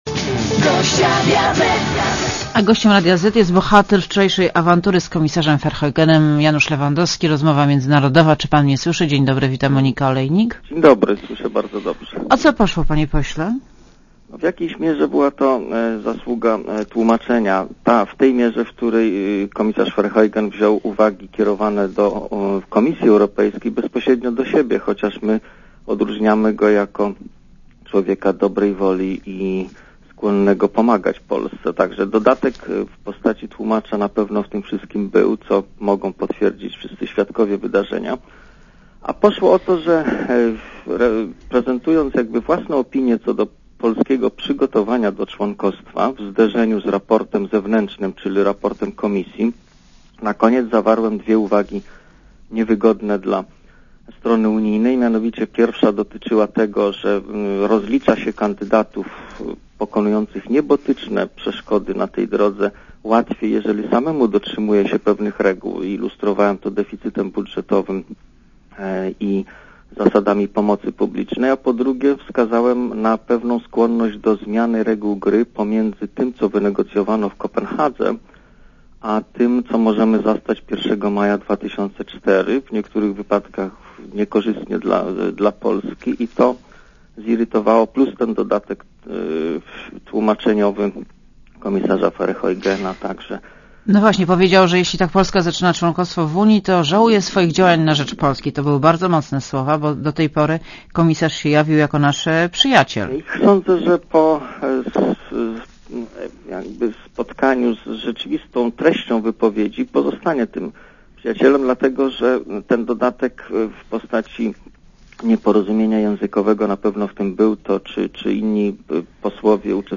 Tymczasem tamte kraje te 3% przekraczają trzykrotnie i co robi Komisja - tylko warczy i pomrukuje trochę - powiedział Janusz Lewandowski w rozmowie z Moniką Olejnik.
Posłuchaj wywiadu (2,44 MB) Francja i Niemcy łamią deficyt budżetowy.